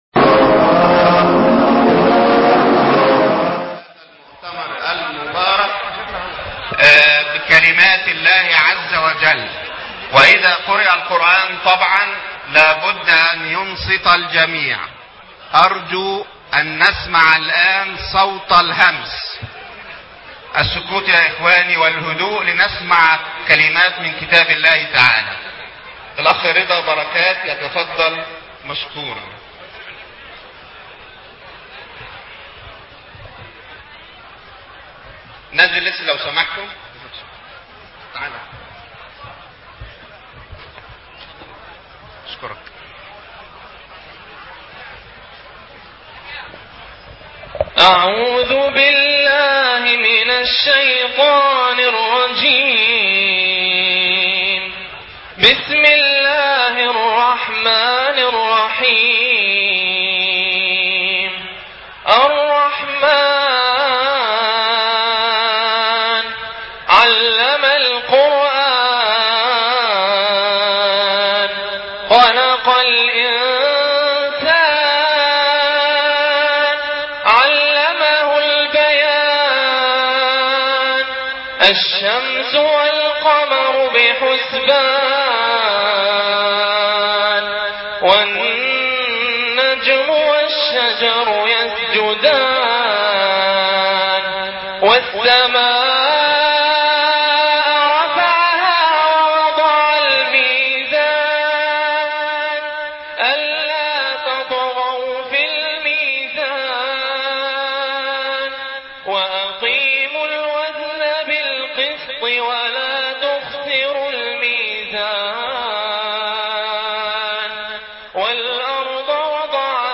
حول التطوير الصناعي في مصر ومؤتمر رائع بدمياط